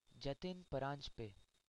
Jatin_Paranjpe.ogg.mp3